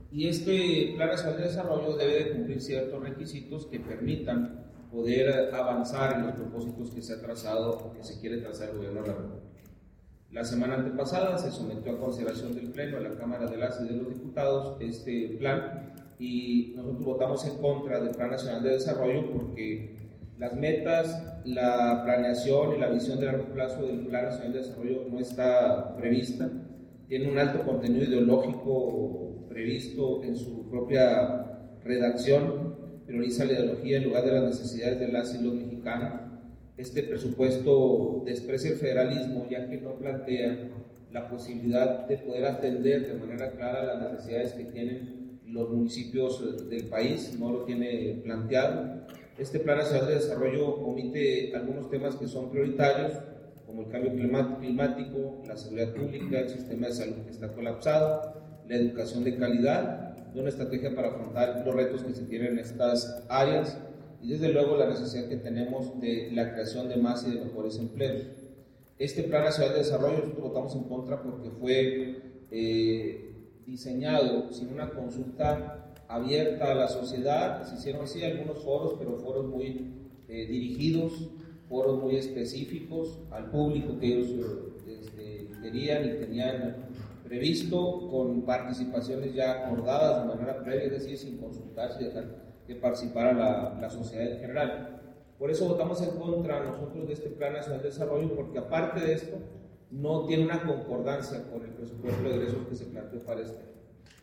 Durante una conferencia de prensa en las instalaciones del Comité Municipal del PRI en Chihuahua, argumentó que el presupuesto priorizaba la ideología sobre las demandas de los municipios, omitiendo temas como el cambio climático, la seguridad pública, el sistema de salud y la creación de empleos de calidad.